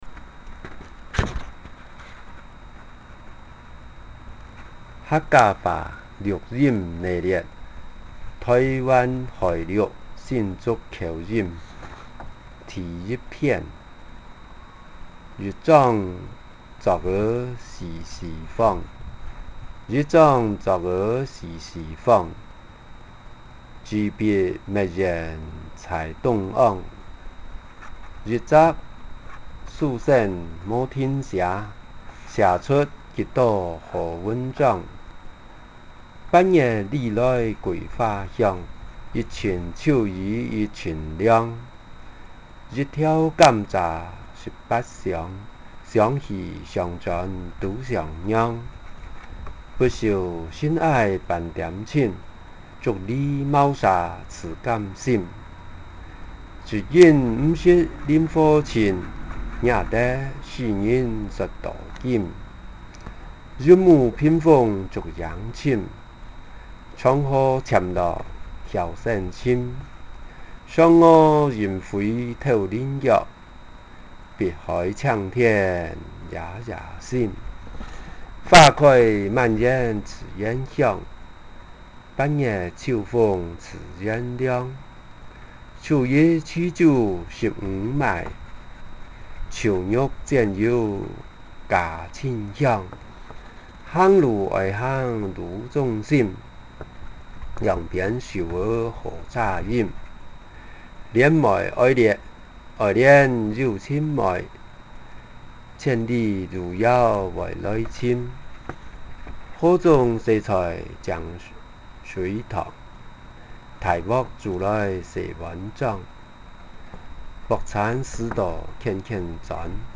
福建三明市清流县城关口音